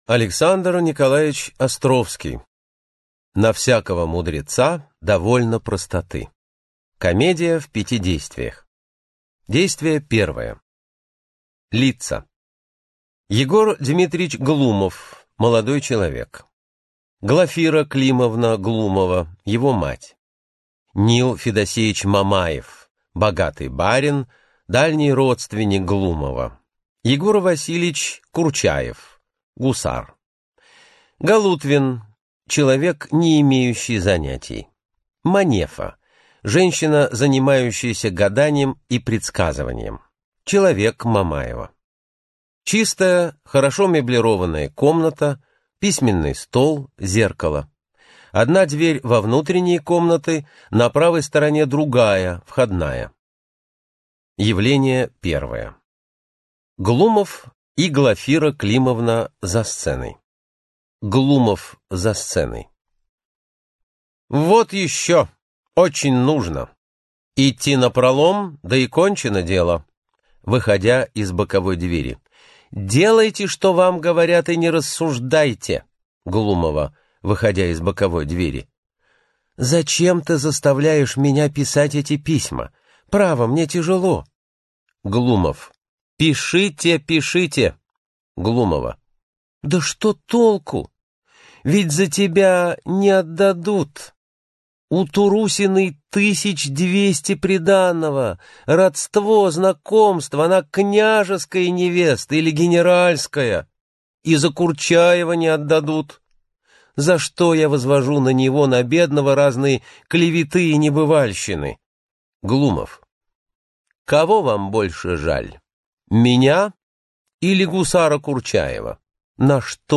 Аудиокнига На всякого мудреца довольно простоты | Библиотека аудиокниг